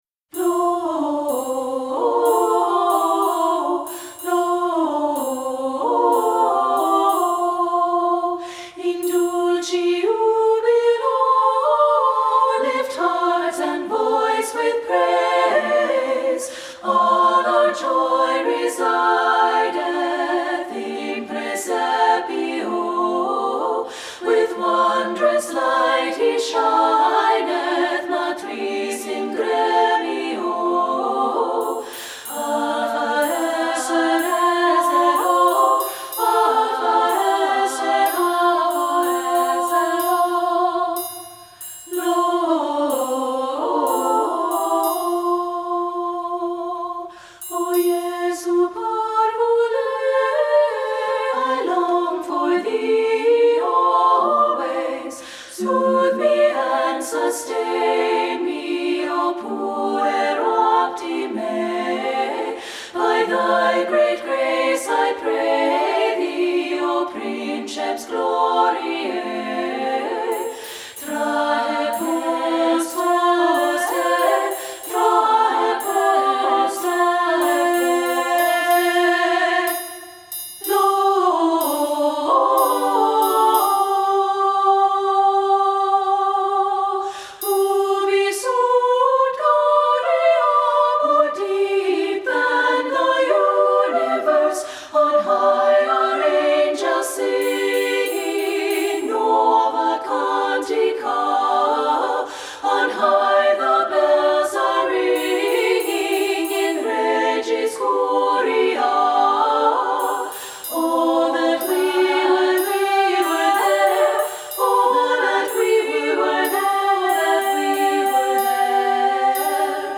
Composer: 14th Century German Carol
Voicing: SSA a cappella